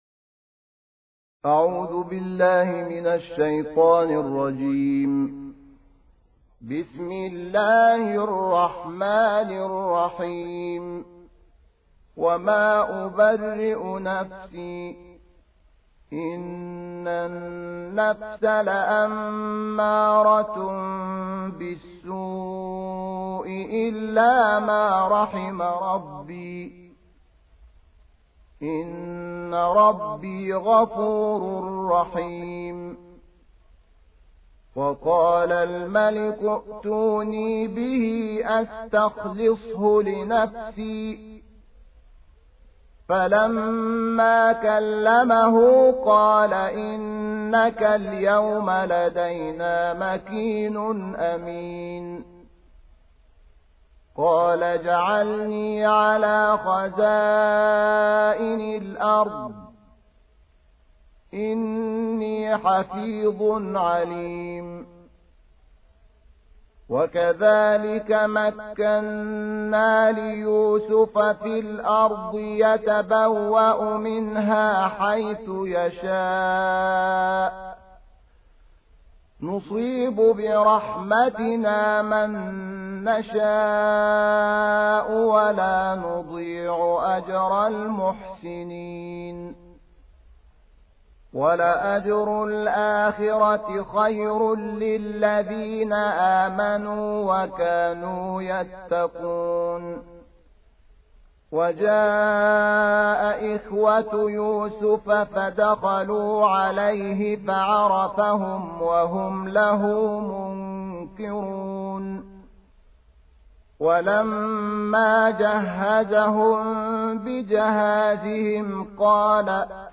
صوت/ ترتیل جزء سیزدهم قرآن توسط "منشاوی"